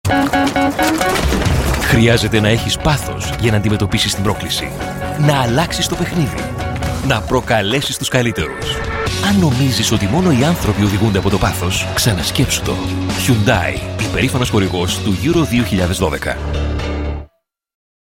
Sprechprobe: Industrie (Muttersprache):
The amazing Greek voice